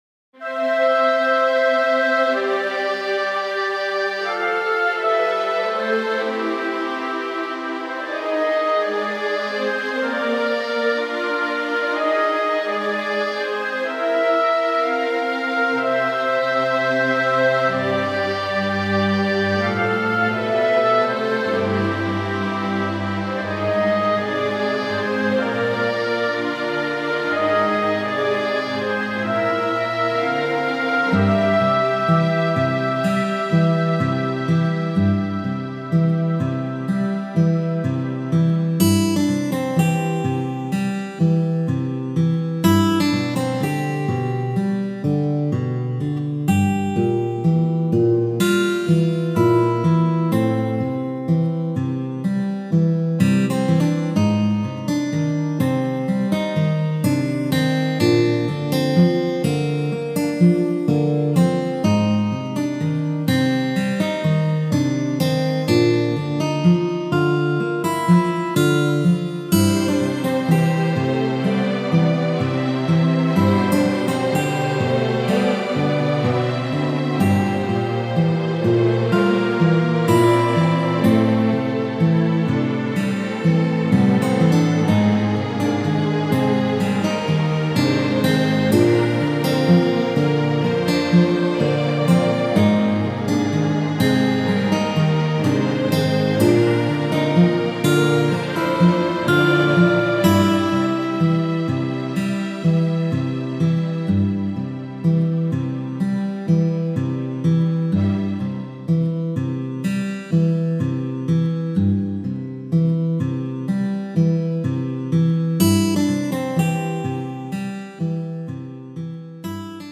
のどかな街の日常の一風景。激しい戦闘から解放された戦士たちを迎える、宿のある村の感じ